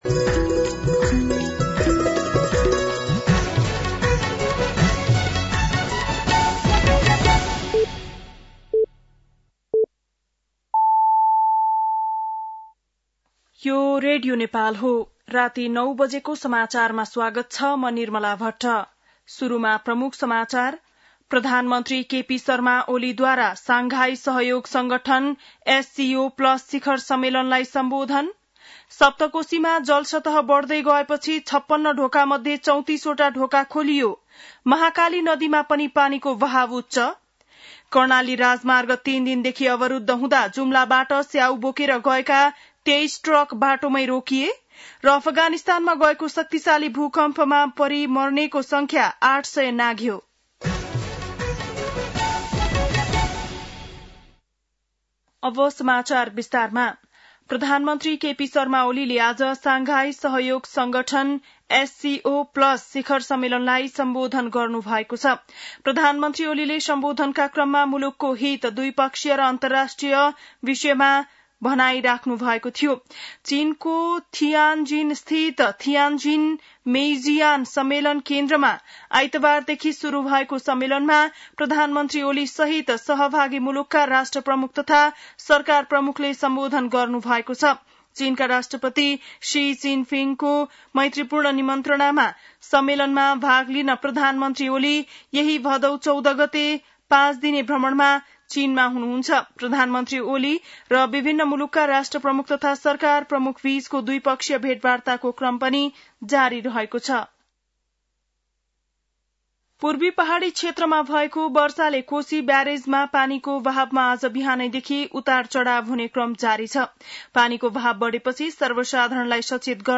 बेलुकी ९ बजेको नेपाली समाचार : १७ भदौ , २०८२
9-PM-Nepali-NEWS-5-16-1.mp3